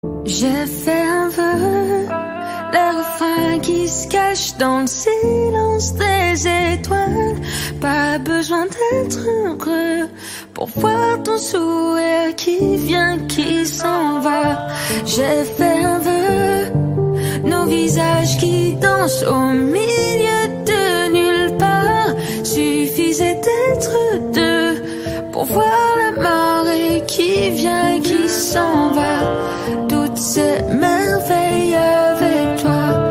Rap / Hip Hop